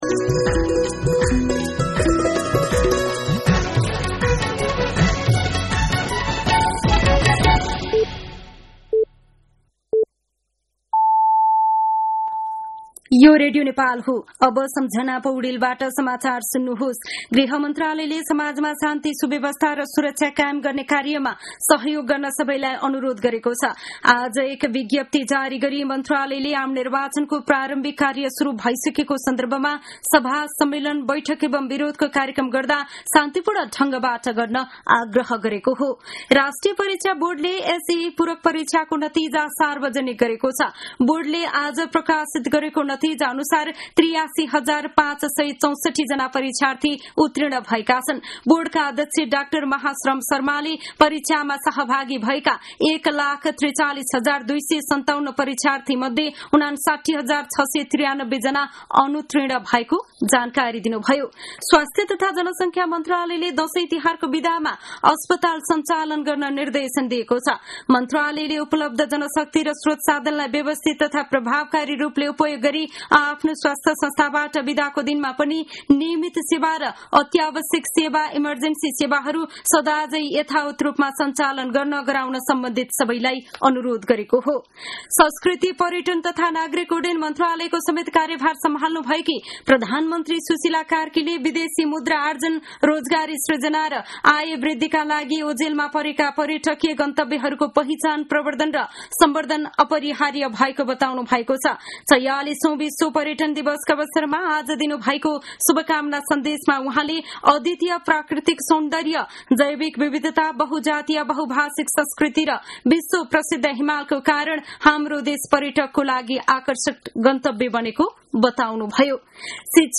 साँझ ५ बजेको नेपाली समाचार : ११ असोज , २०८२
5.-pm-nepali-news-1-8.mp3